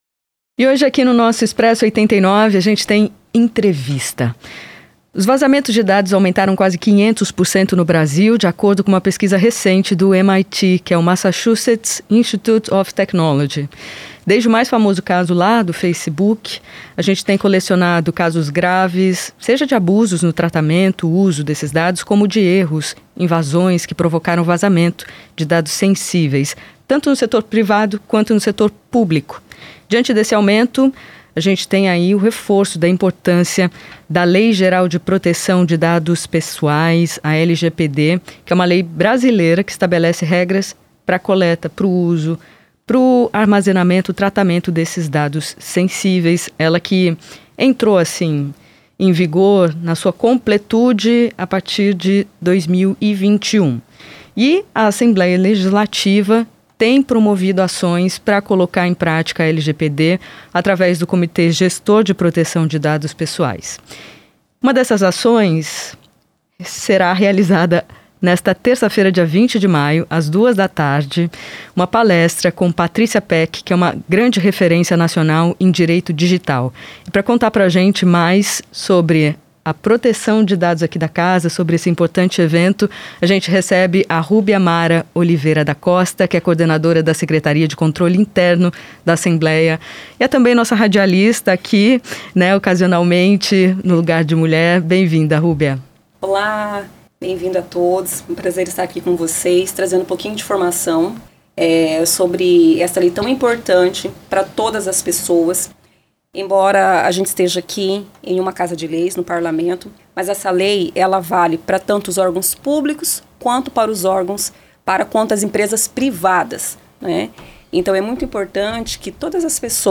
Expresso 89 Entrevista